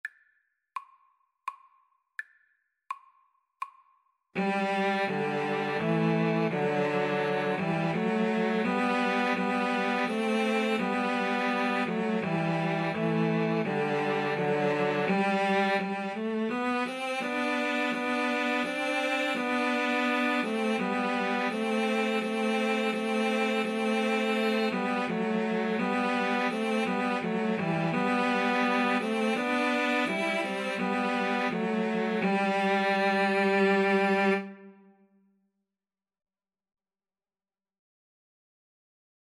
3/4 (View more 3/4 Music)
Cello Trio  (View more Easy Cello Trio Music)